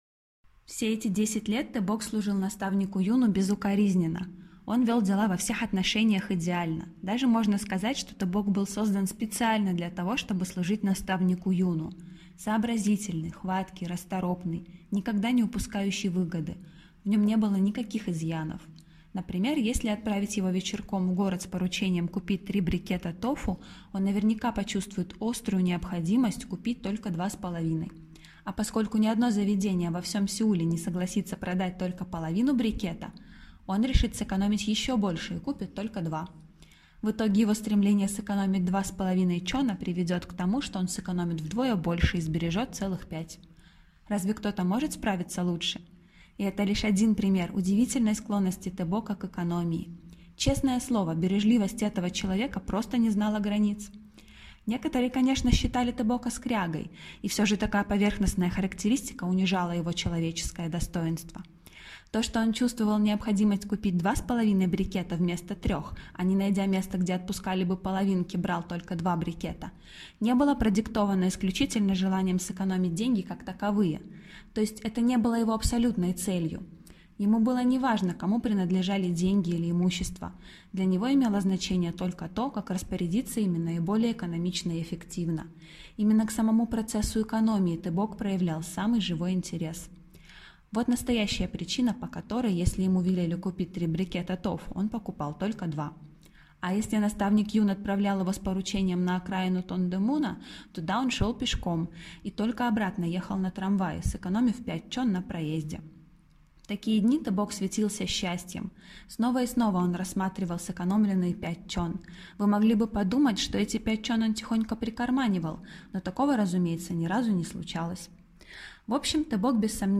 Каждый переводчик читает свой текст; вы услышите 11 голосов и 11 неповторимых творческих интонаций.